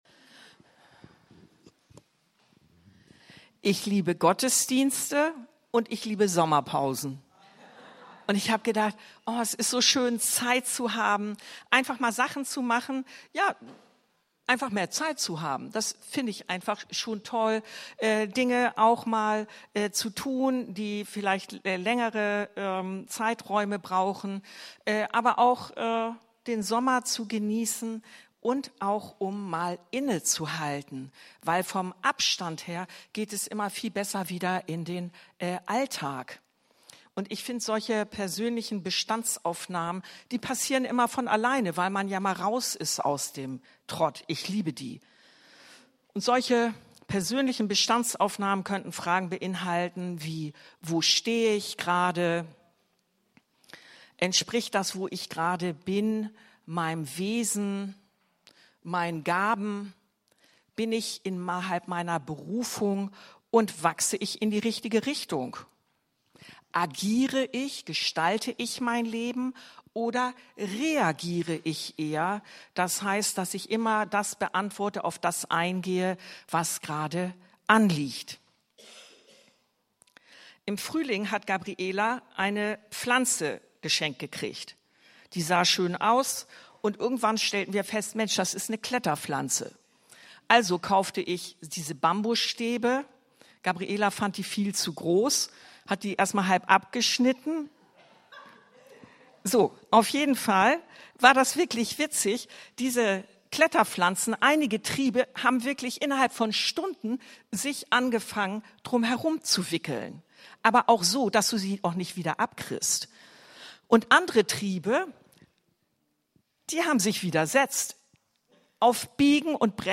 Nutze deine Zeit ~ Anskar-Kirche Hamburg- Predigten Podcast